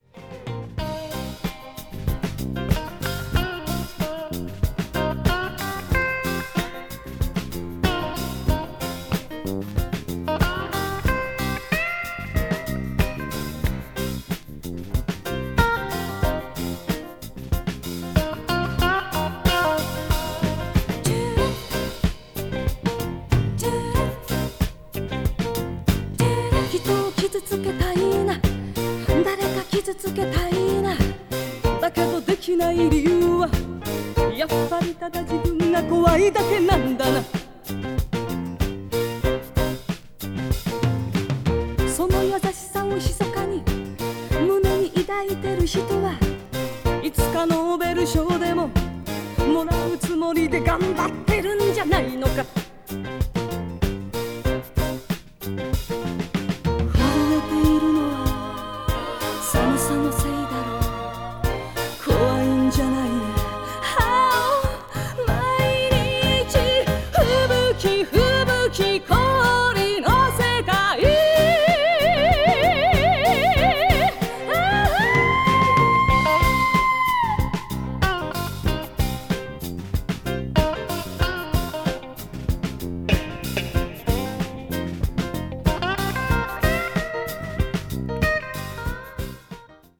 a slow funk-infused arrangement